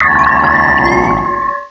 pokeemerald / sound / direct_sound_samples / cries / bronzong.aif